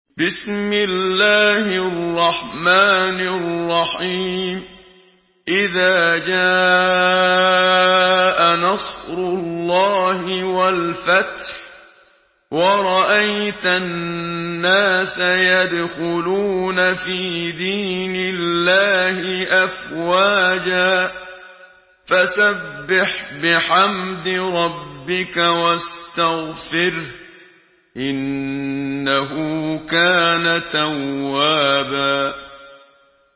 Surah An-Nasr MP3 by Muhammad Siddiq Minshawi in Hafs An Asim narration.
Murattal Hafs An Asim